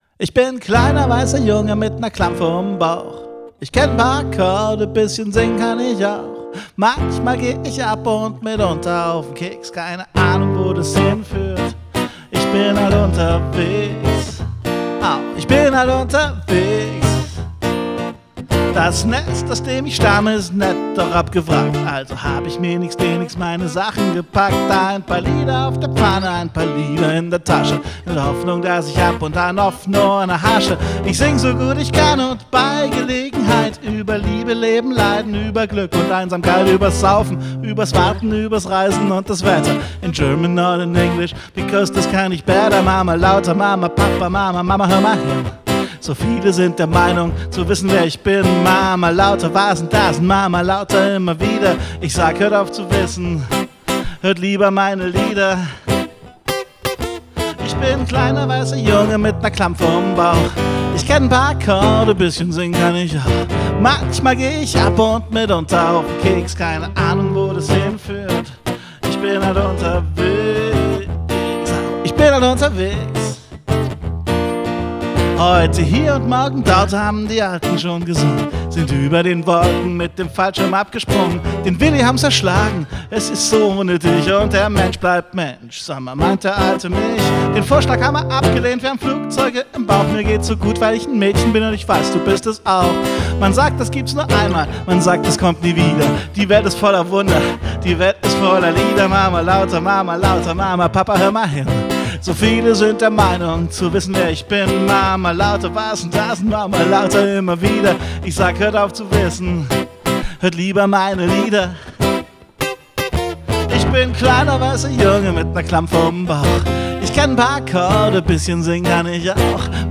Aufgenommen und gemischt am 29. Juli 2017